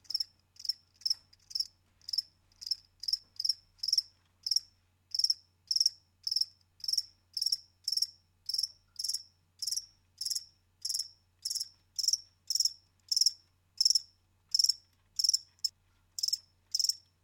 insectnight_1.ogg